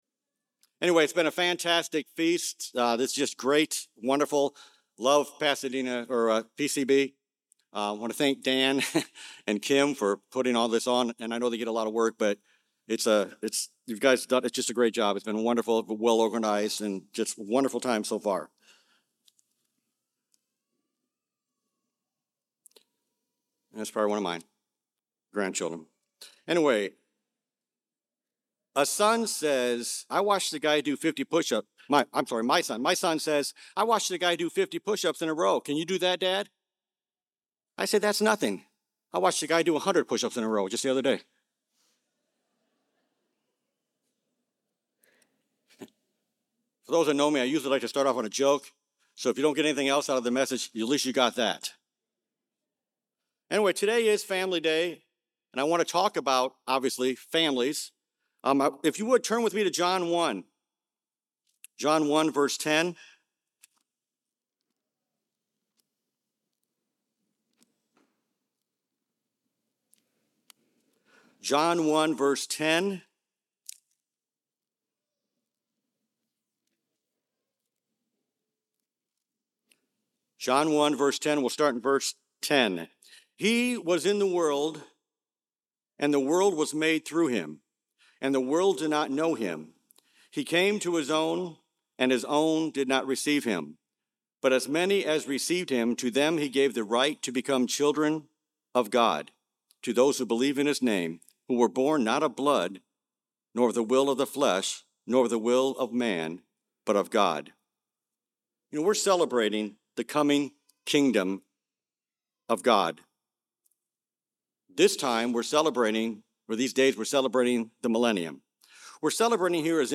This sermon was given at the Panama City Beach, Florida 2022 Feast site.